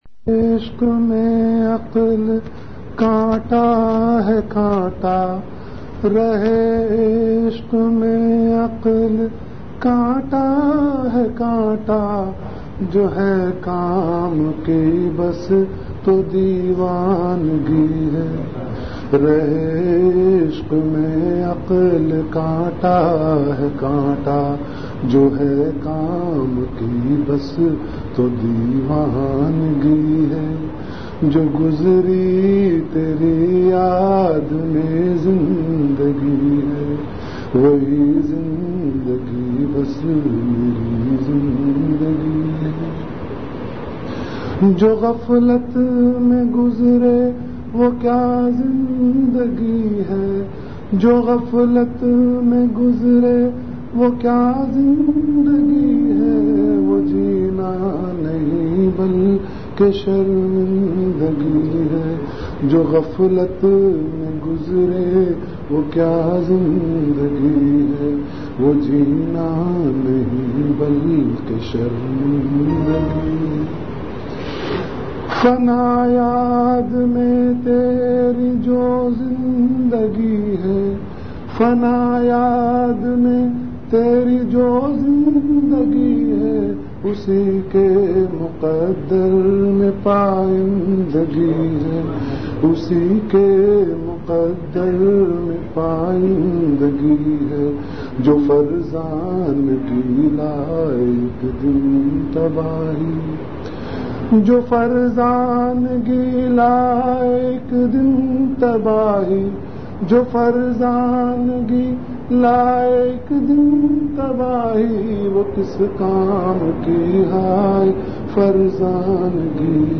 Category Majlis-e-Zikr
Venue Home Event / Time After Isha Prayer